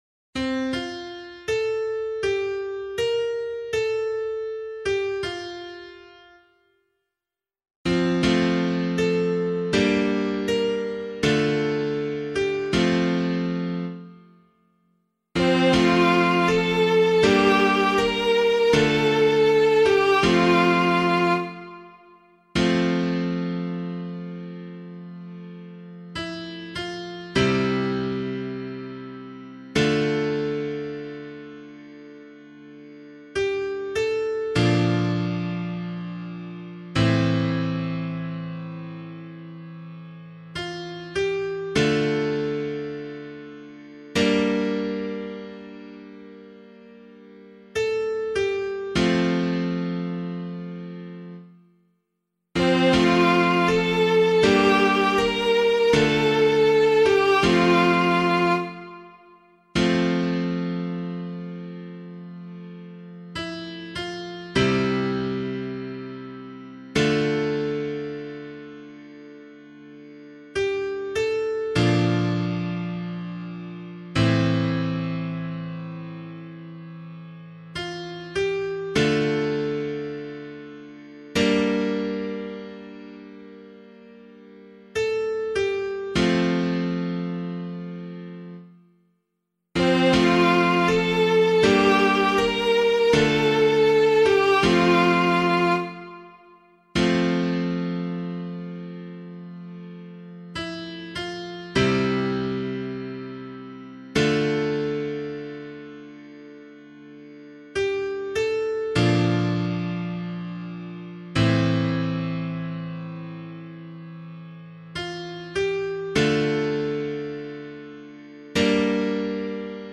041 Ordinary Time 7 Psalm C [LiturgyShare 6 - Oz] - piano.mp3